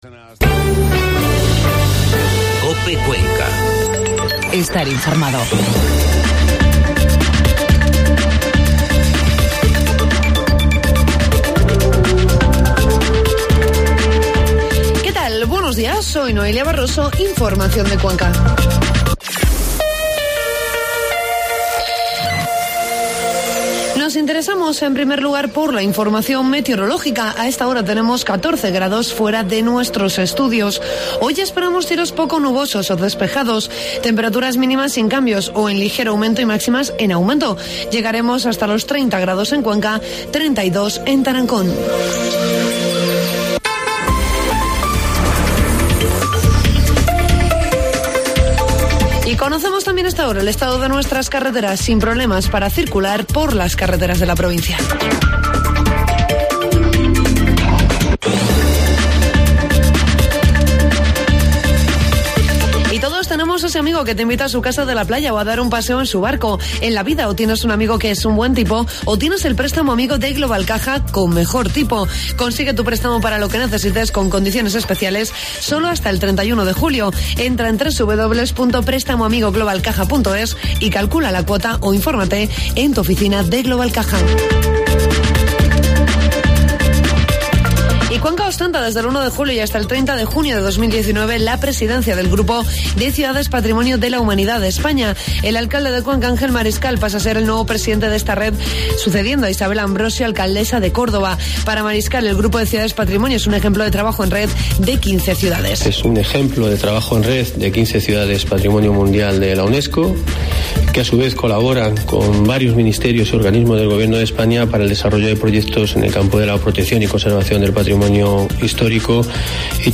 Informativo matinal 3 de julio